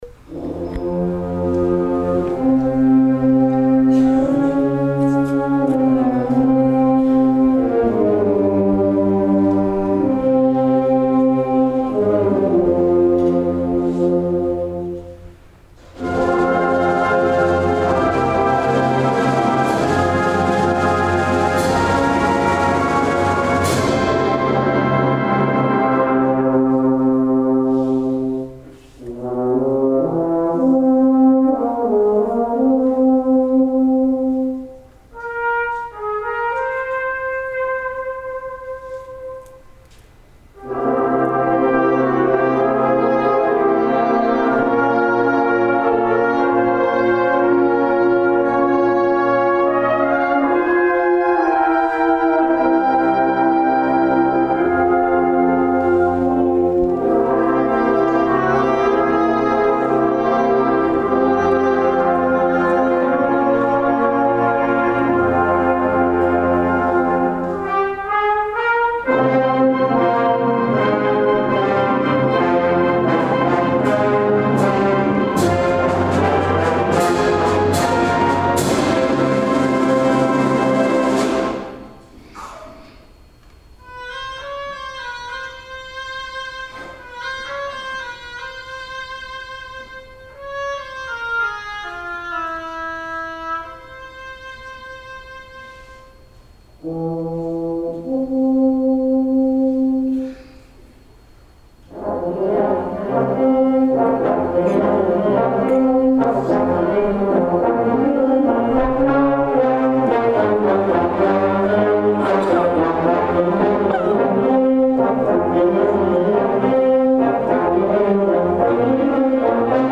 Overture